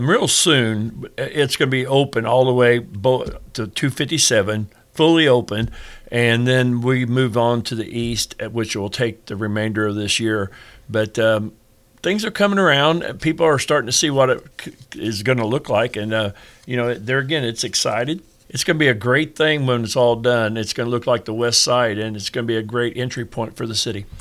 Washington Mayor Dave Rhoads gave us an update this week on the progress of the Business 50 Project through town.
nz-mayor-rhoads-on-50.mp3